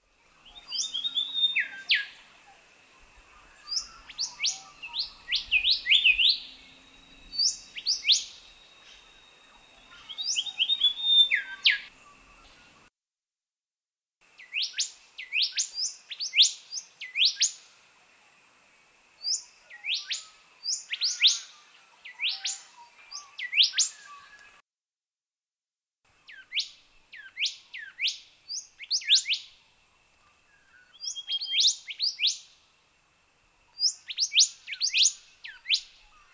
They also differ in habits and call – click on the audio bars below to hear local examples of the calls.
Golden Whistler
golden-whistler.mp3